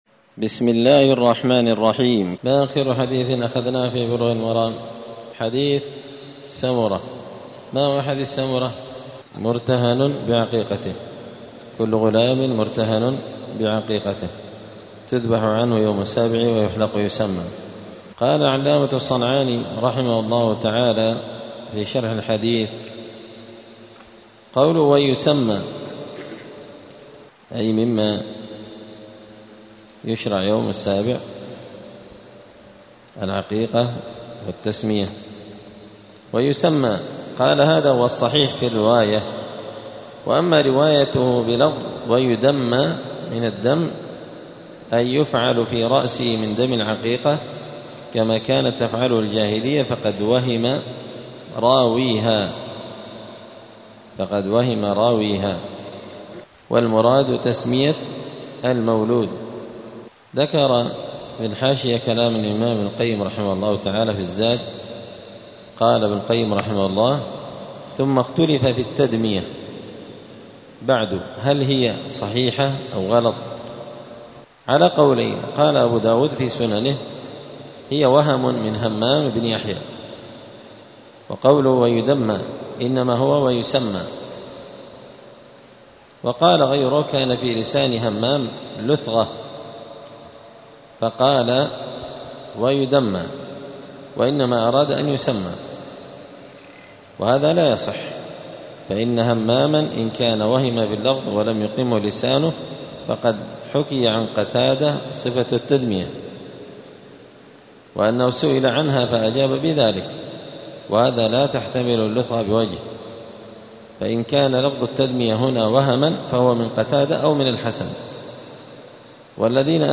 *الدرس الواحد والثلاثون (31) {باب العقيقة اﺭﺗﻬﺎﻥ اﻟﻐﻼﻡ ﺑﻌﻘﻴﻘﺘﻪ}*